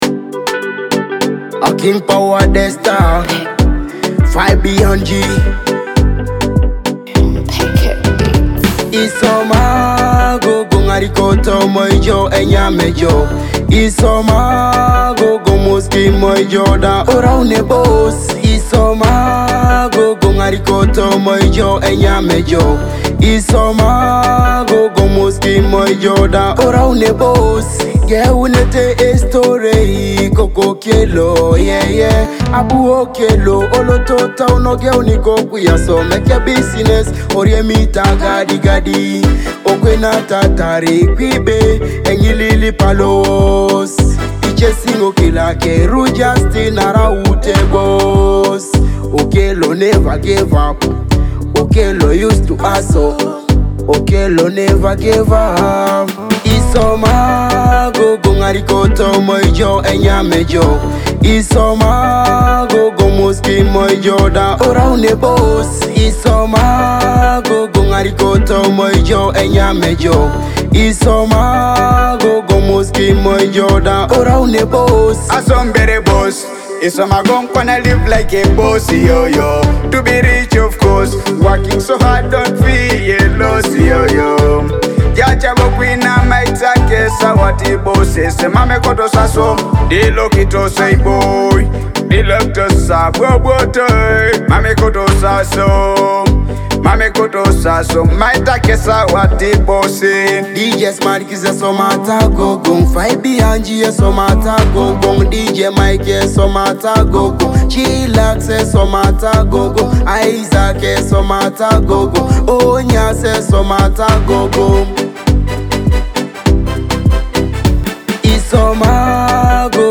your premier destination for Teso music!